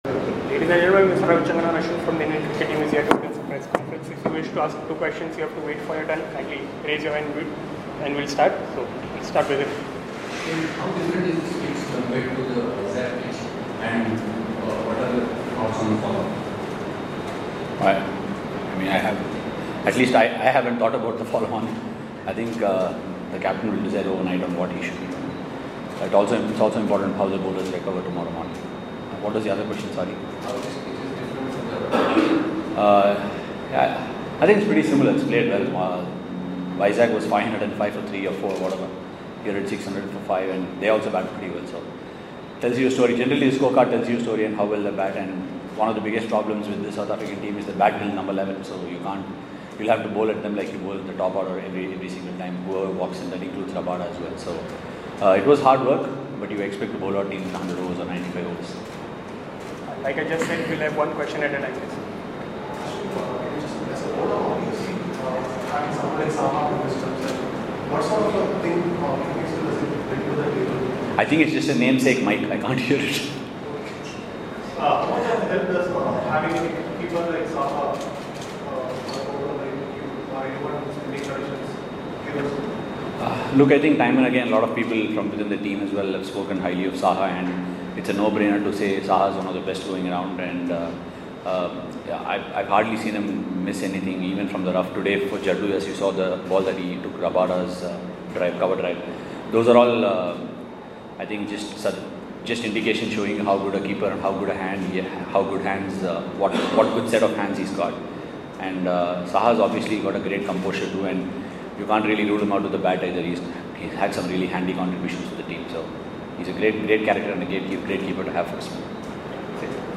Ravichandran Ashwin spoke to the media after 3rd day's play of the 2nd Paytm Test of the Freedom Series for Gandhi-Mandela Trophy at Maharashtra Cricket Association Stadium, Pune.